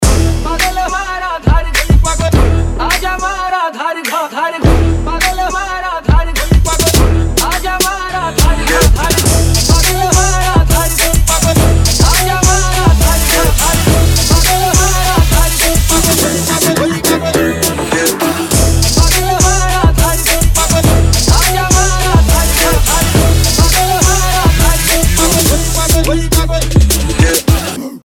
dance
Trap
восточные
арабские